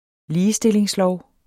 Udtale [ ˈliːəˌsdeleŋs- ]